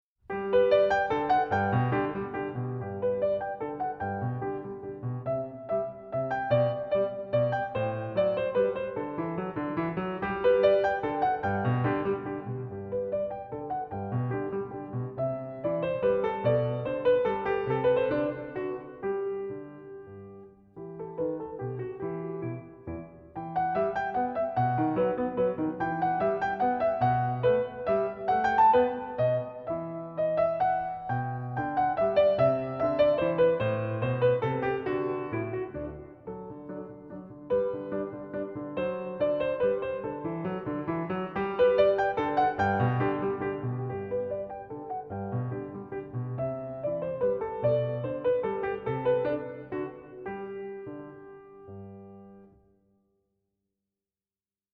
Besetzung: Instrumentalnoten für Klavier